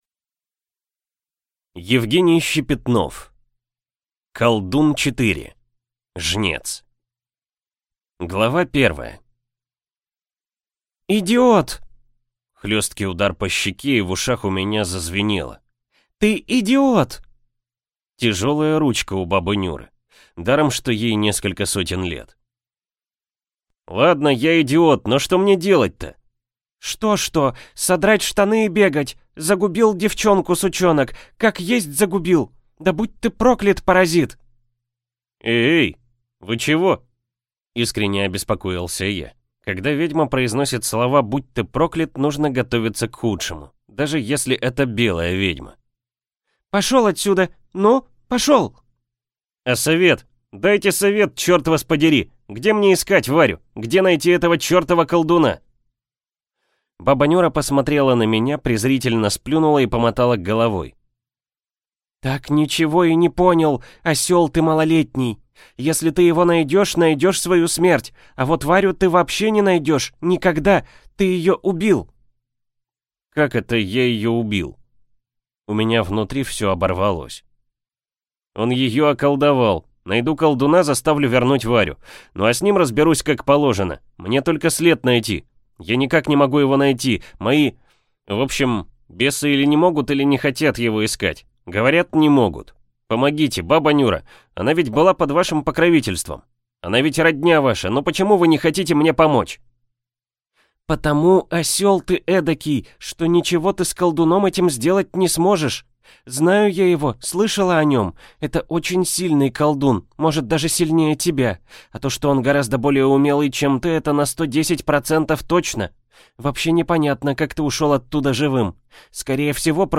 Аудиокнига Жнец | Библиотека аудиокниг
Прослушать и бесплатно скачать фрагмент аудиокниги